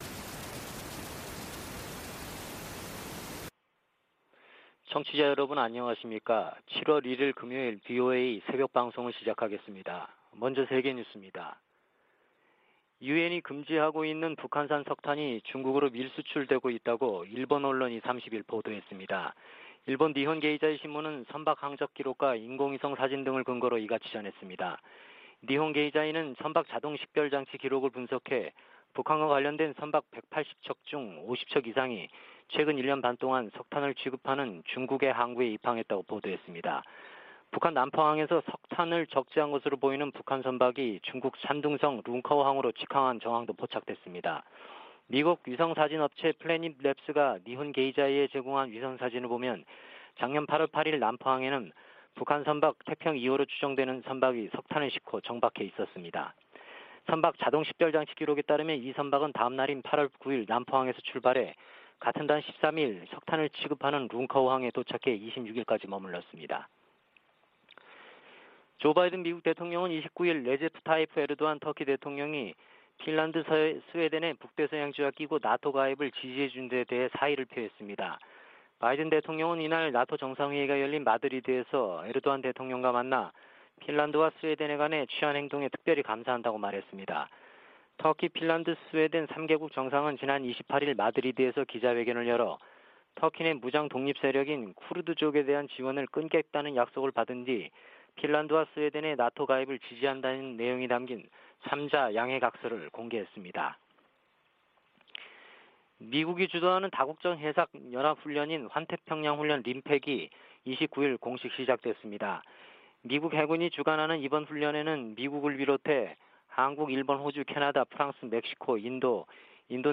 VOA 한국어 '출발 뉴스 쇼', 2022년 7월 1일 방송입니다. 백악관은 조 바이든 대통령이 한국·일본 정상과 협력 심화를 논의한 역사적인 회담을 열었다고 발표했습니다. 미 공화당 상원의원들은 나토가 중국과 러시아의 위협에 동시에 대응하도록 할 것을 바이든 대통령에게 촉구했습니다. 냉각된 한일관계에 개선은 대북 억제와 중국 견제를 위해 중요하다고 미국 전문가들이 진단했습니다.